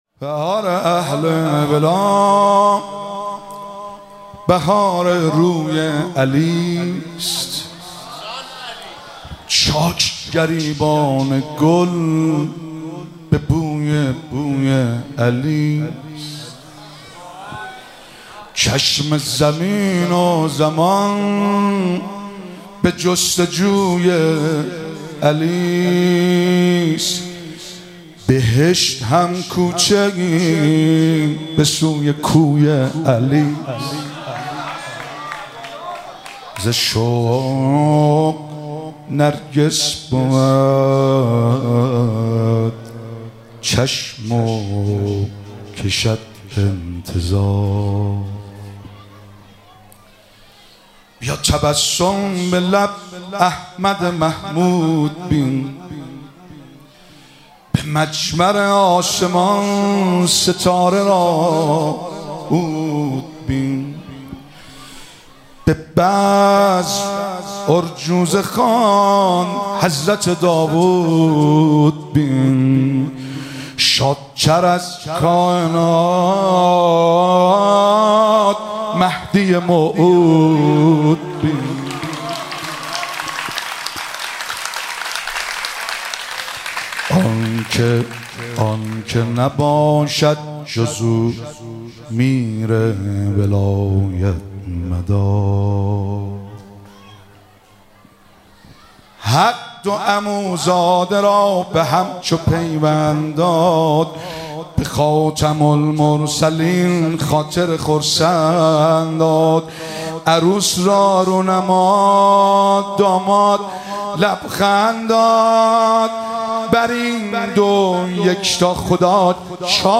مراسم جشن سالگرد ازدواج حضرت امیرالمومنین علی علیه السلام و حضرت فاطمه زهرا سلام الله علیها- خرداد 1402
مناسبت روضه هفتگی